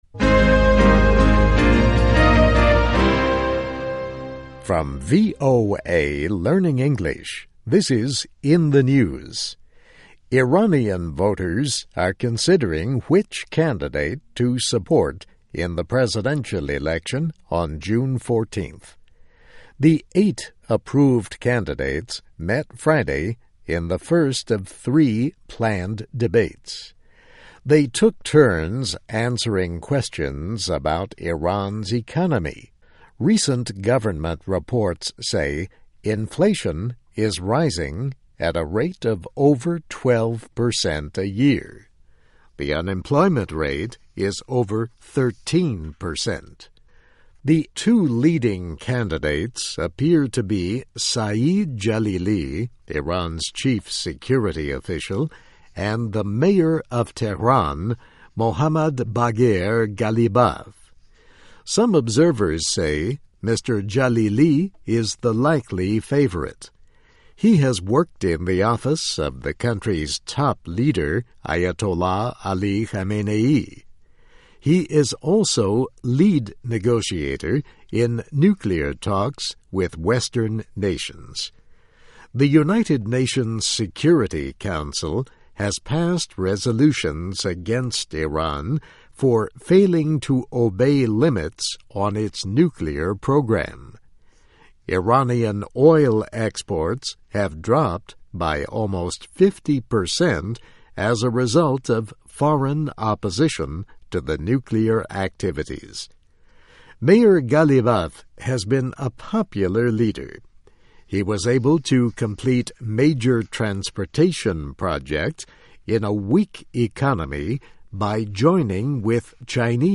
VOA慢速英语, In the News, 伊朗总统候选人举行首轮辩论